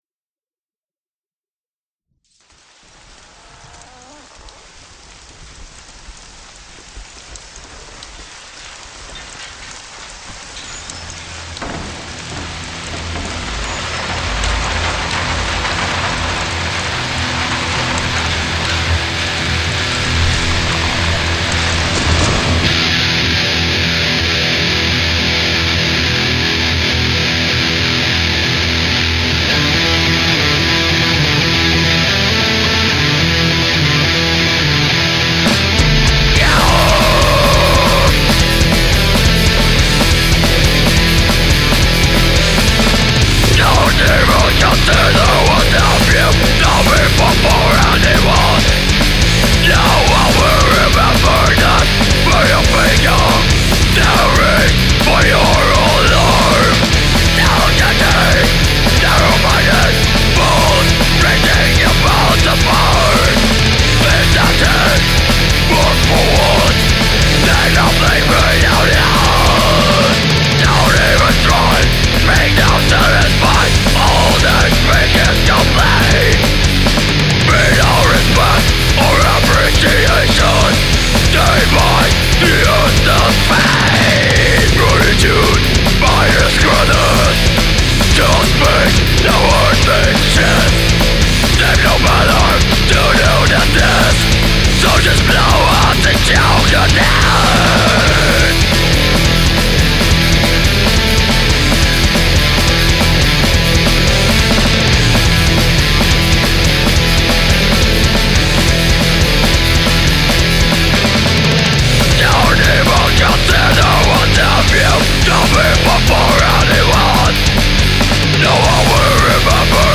Gitár
Ének